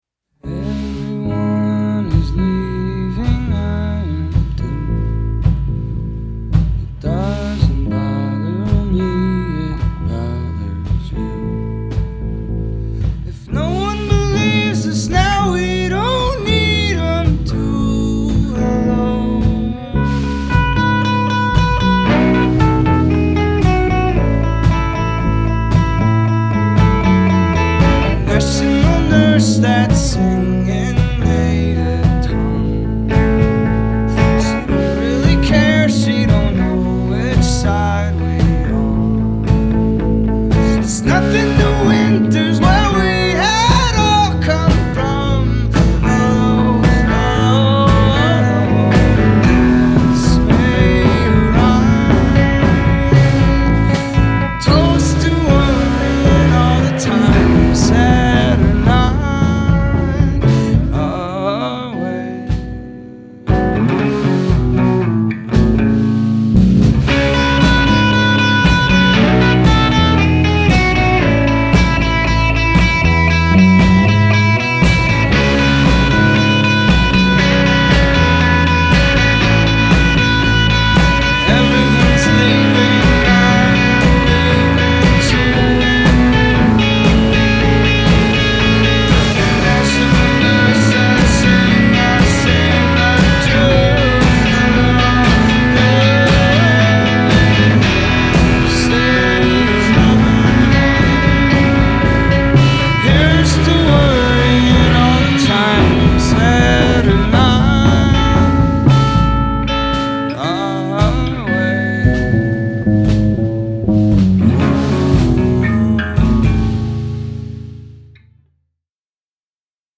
The songs were recorded live